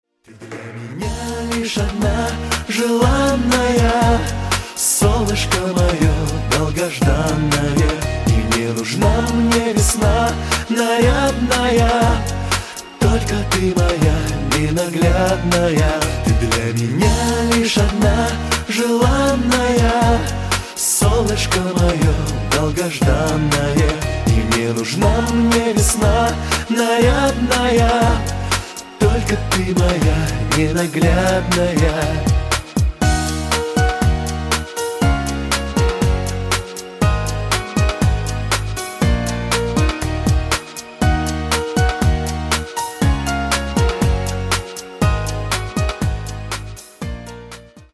• Качество: 128, Stereo
поп
красивые